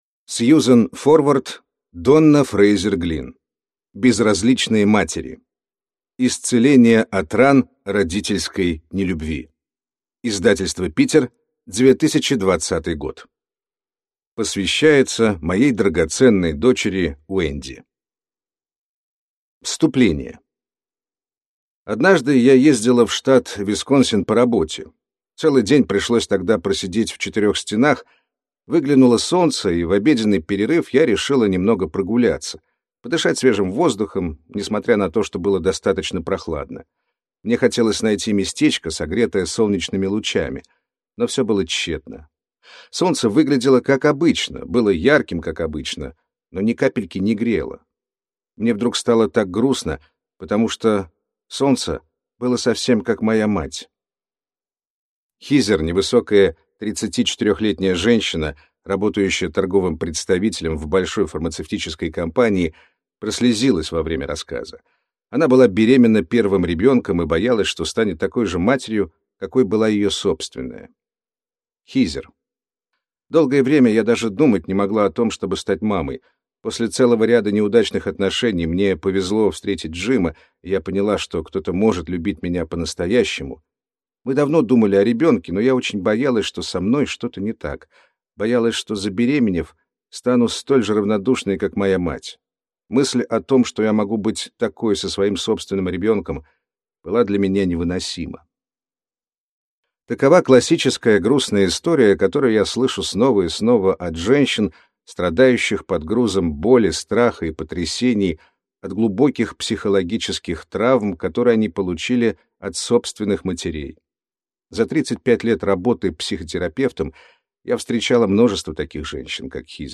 Аудиокнига Безразличные матери. Исцеление от ран родительской нелюбви | Библиотека аудиокниг